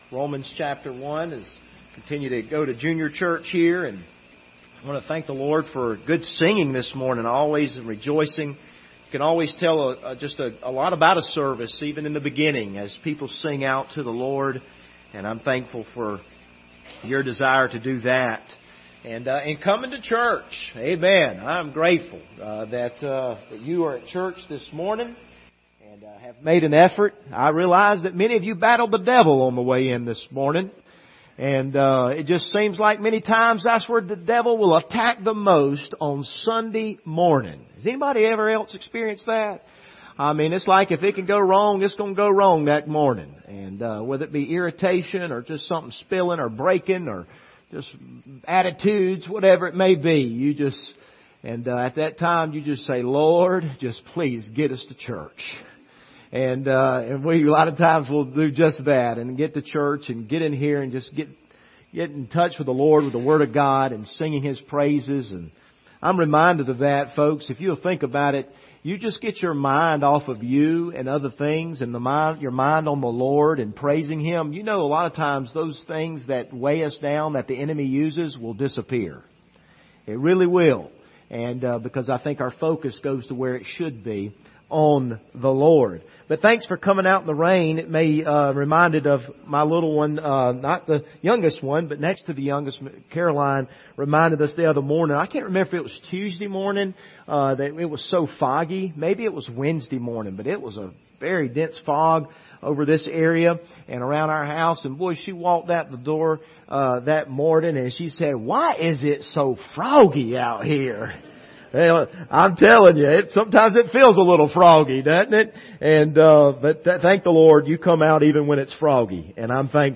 Passage: Romans 1:1-6 Service Type: Sunday Morning